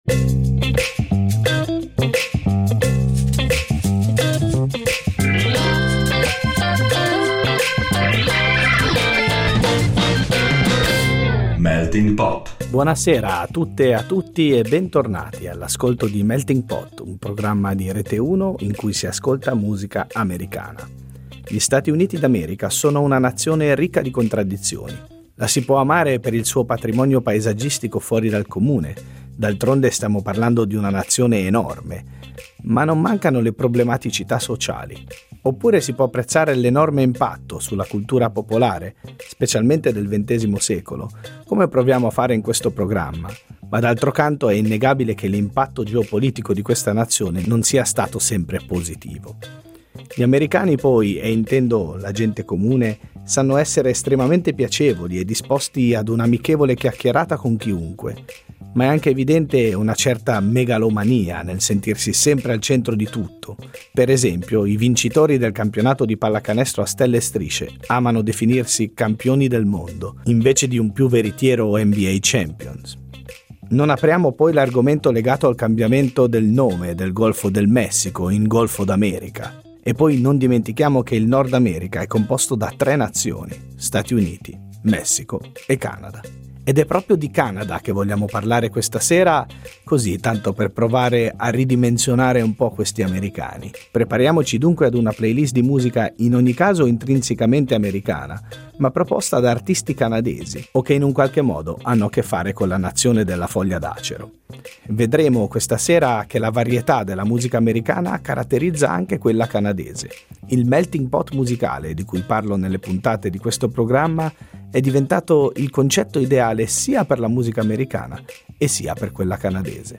La quarta puntata di Melting Pot esplora la musica canadese ispirata alle radici americane.